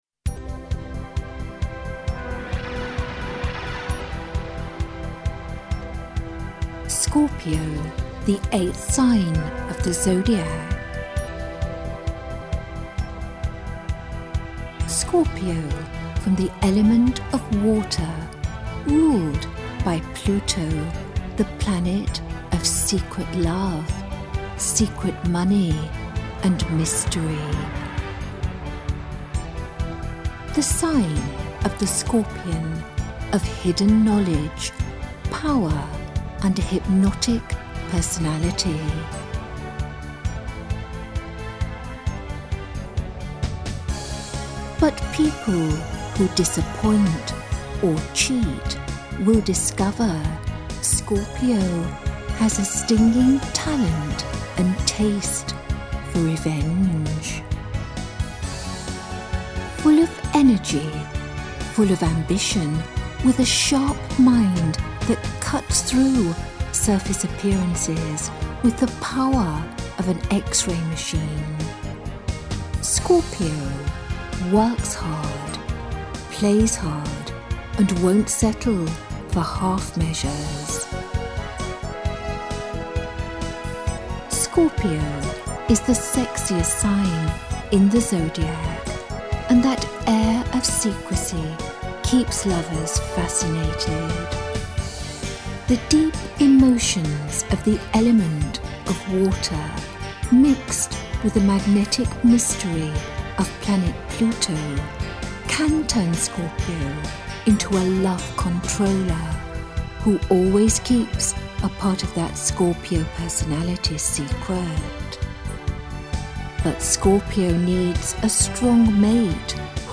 Listen FREE to Meg describing Scorpio